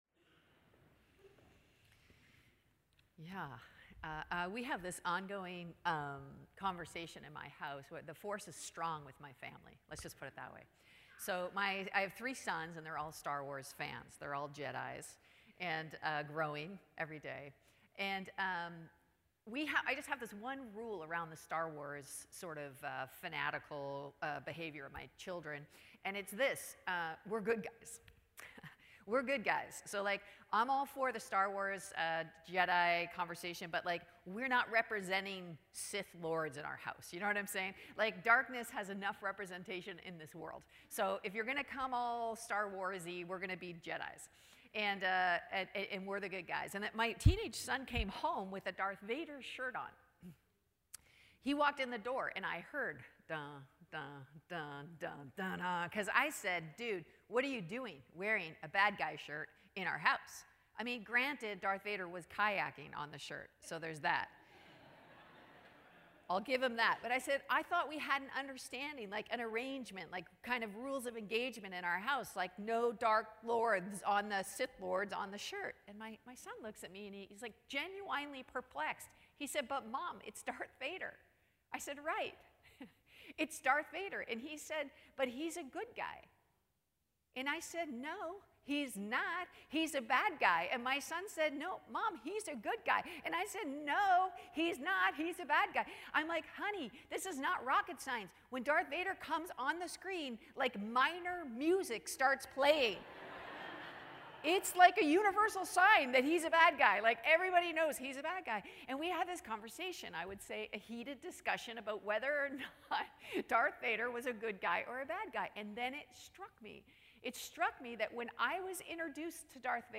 Chapel services, 2019